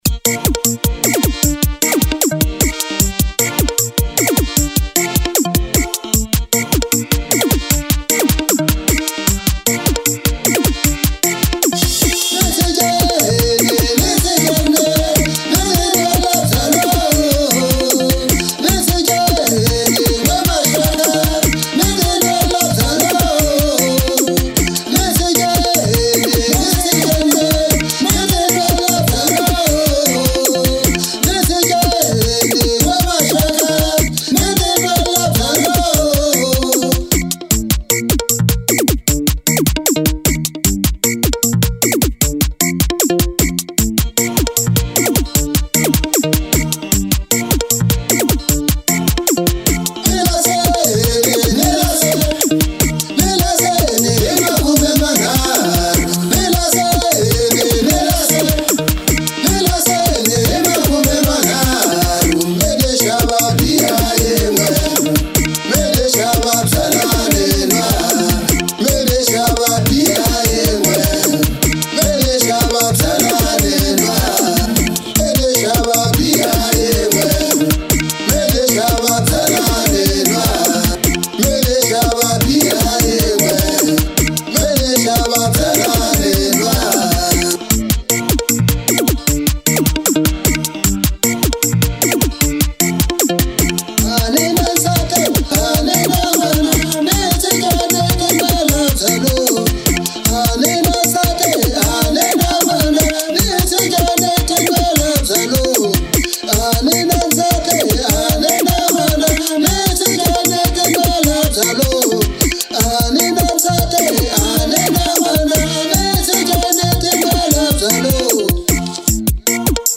04:12 Genre : Xitsonga Size